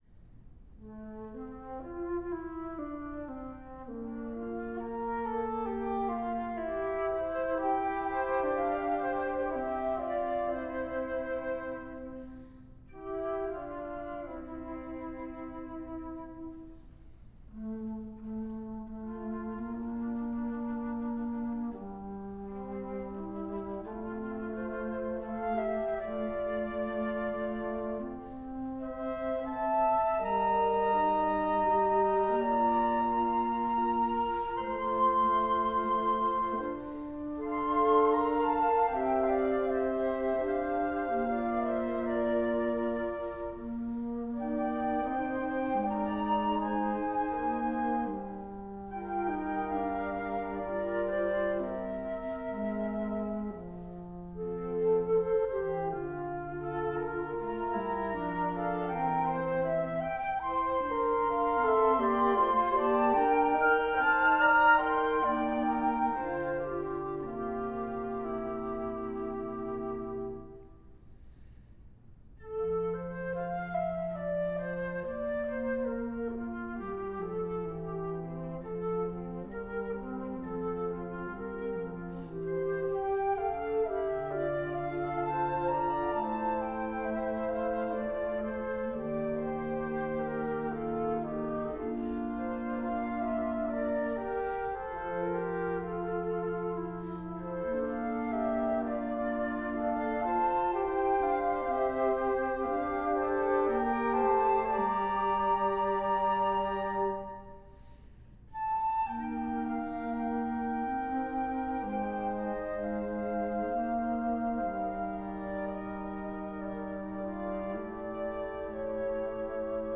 Arranged for Flute Quartet